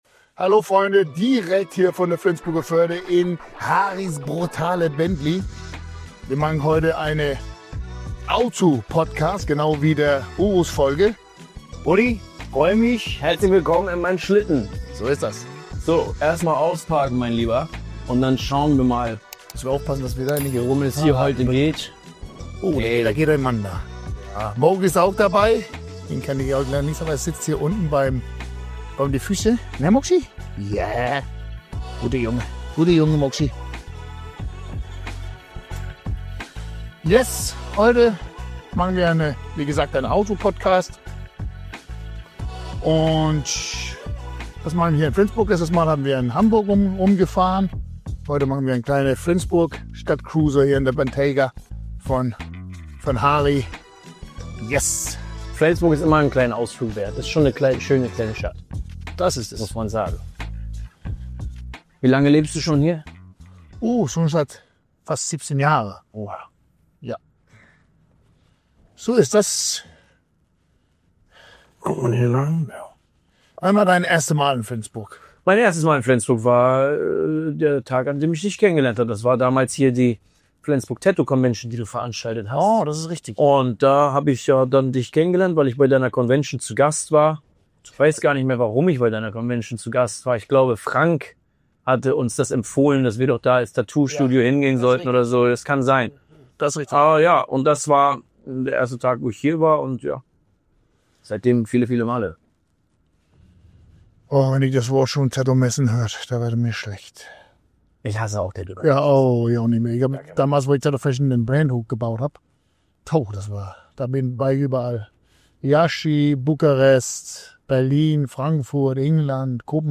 Diesmal eine neue Carpodcast Folge im Bentley durch die Straßen Flensburgs. Wir sprechen über unser Mindset und über die Macht deiner eigenen Gedanken.